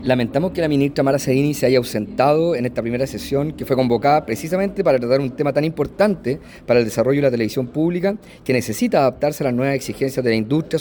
El diputado del Frente Amplio, Ignacio Achurra, lamentó la ausencia de la ministra.